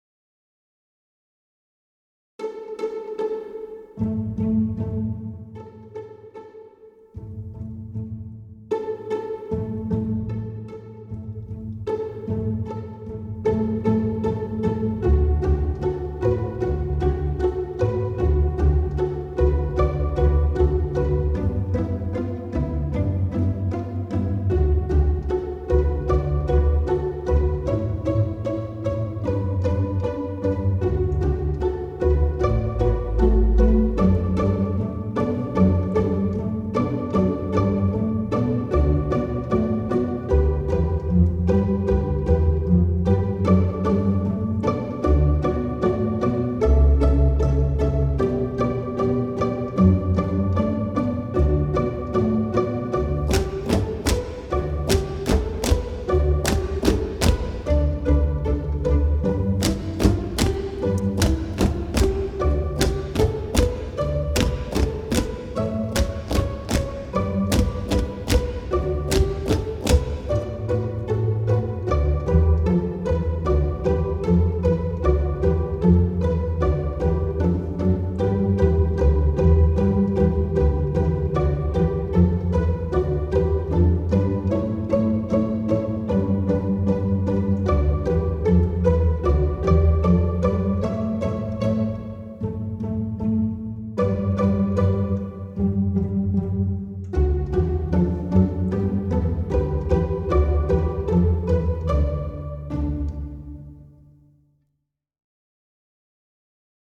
Voicing: String Orchestsra